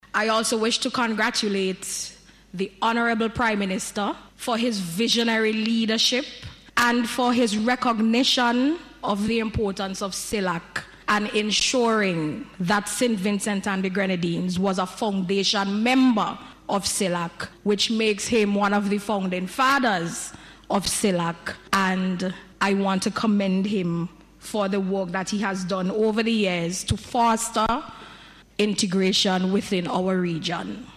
Minister Peters was making congratulatory remarks in the House of Assembly this morning.